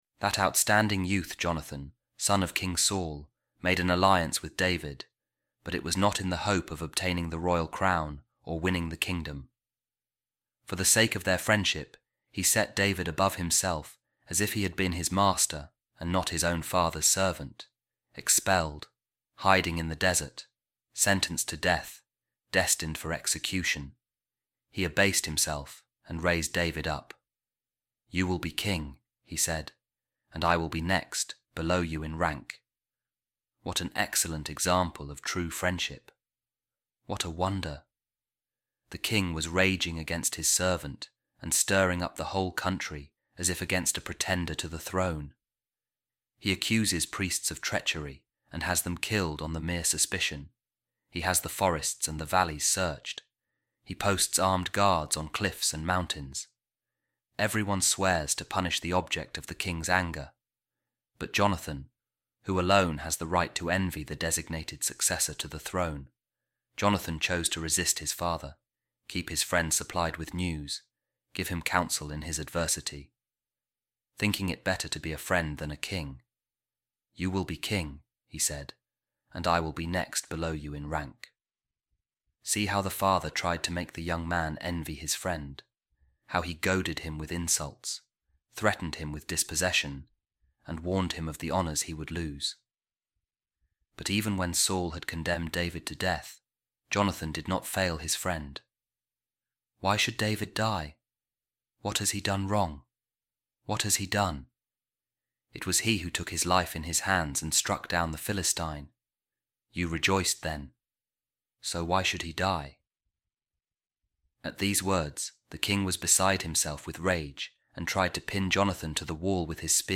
A Reading From The Treatise Of Saint Aelred On Spiritual Friendship | True And Perfect Friendship | Jesus Christ